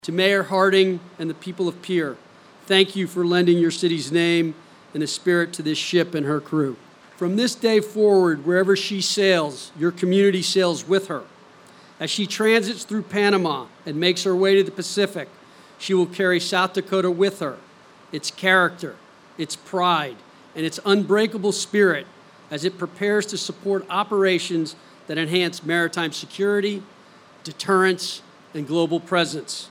PANAMA CITY, F.L.(KCCR)- The U-S-S Pierre officially joined the fleet of the United States Navy Saturday morning with a traditional Commissioning Ceremony at Port Panama City Florida.
Secretary of the Navy Phelan delivered a special message to the residents of Pierre during the event.